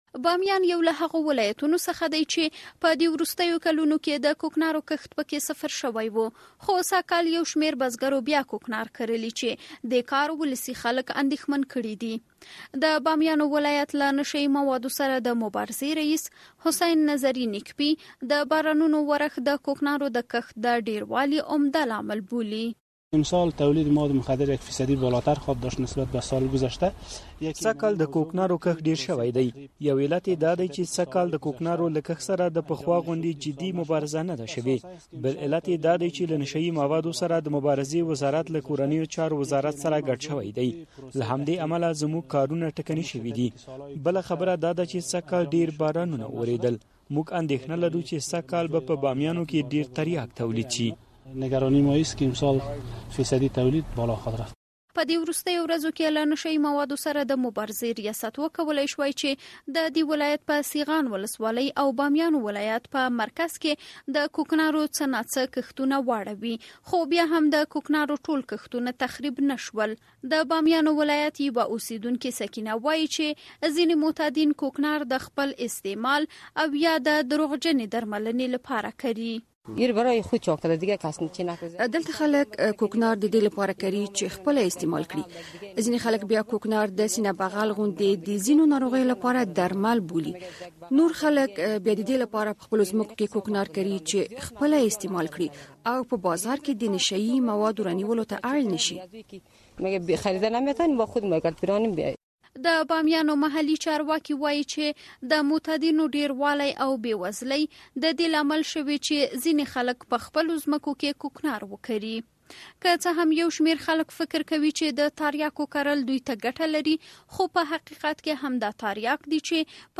د بامیان راپور